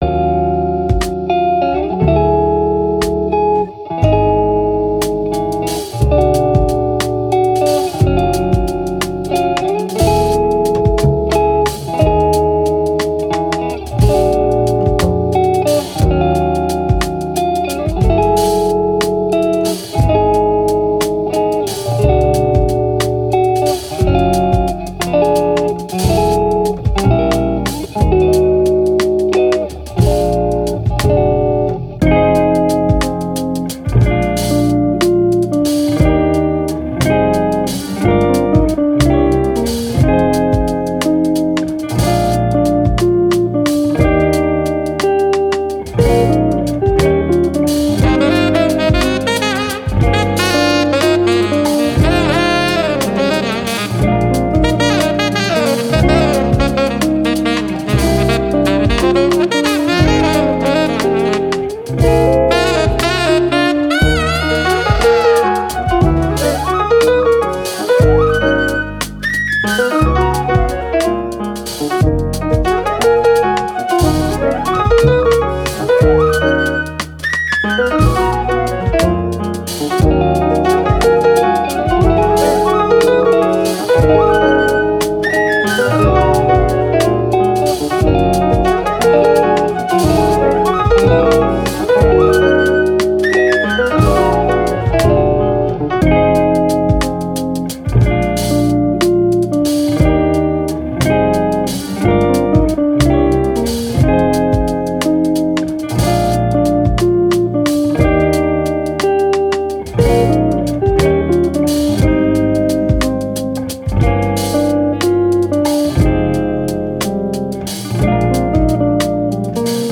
Jazz, Chill, Thoughtful, Elegant, Saxophone